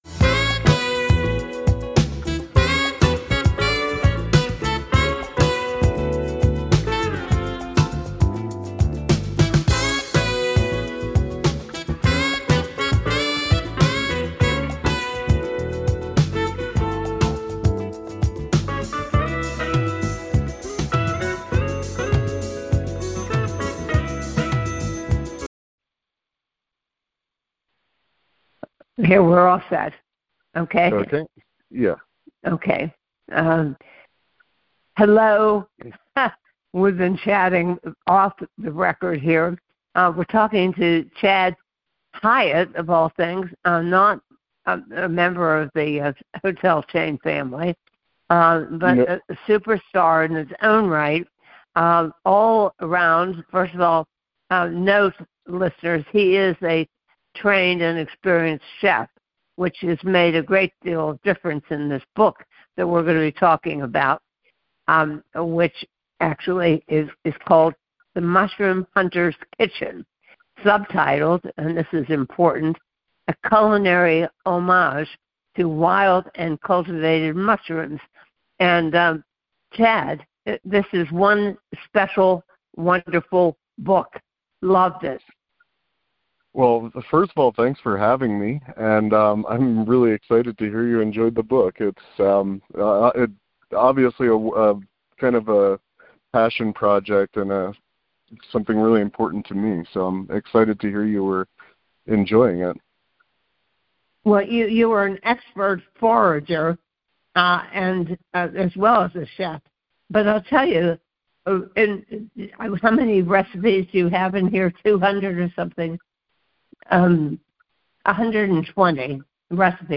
Play Rate Listened List Bookmark Get this podcast via API From The Podcast A husband and wife duo, they interview chefs, restaurateurs, hoteliers, authors, winemakers, food producers, cookware and kitchen gadget makers and other culinary luminaries.